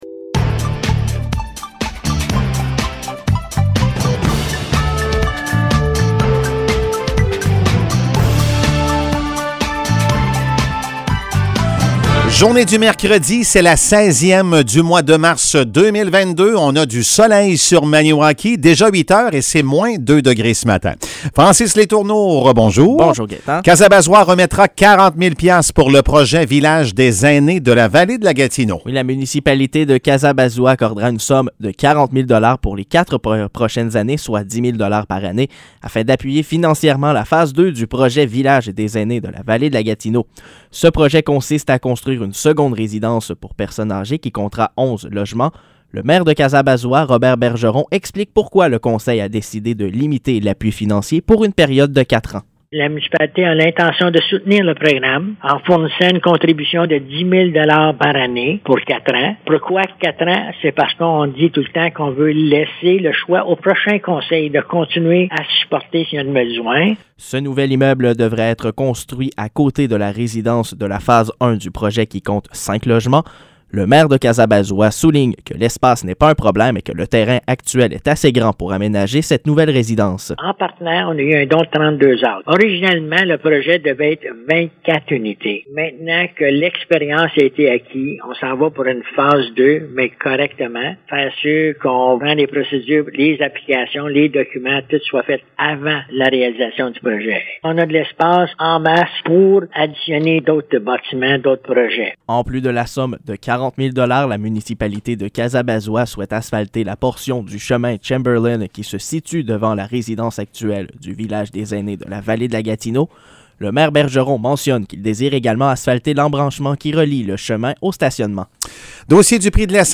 Nouvelles locales - 16 mars 2022 - 8 h